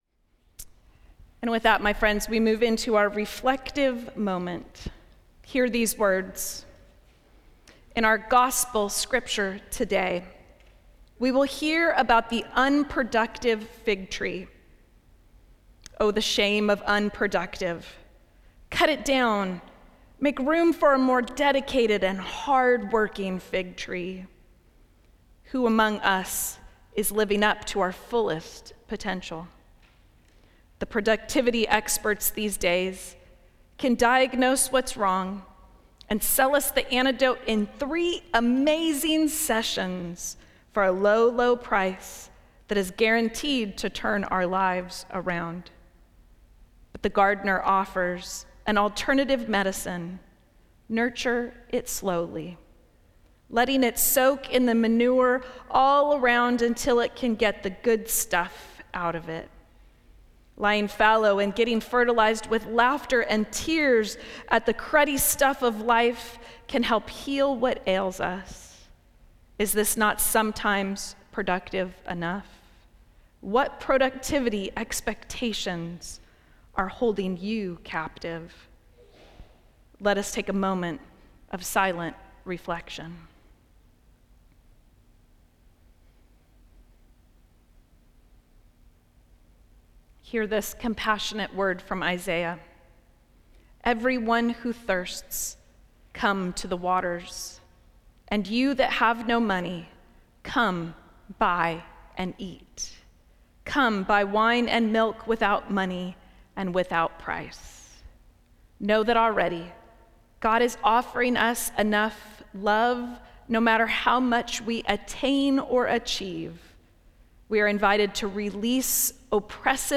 Service of Worship
Reflective Moment / Moment of Silence